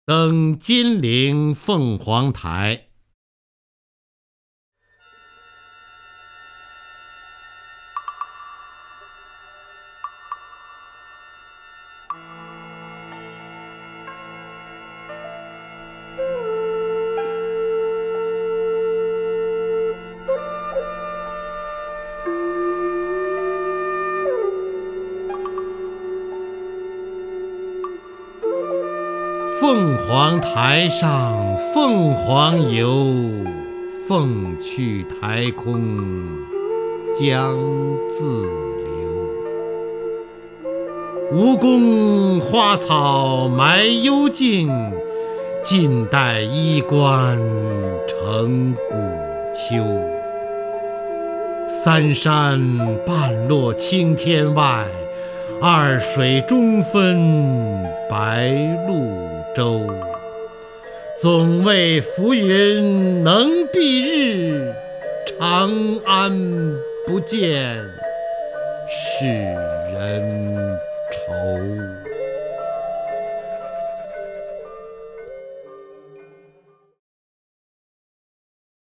首页 视听 名家朗诵欣赏 方明
方明朗诵：《登金陵凤凰台》(（唐）李白)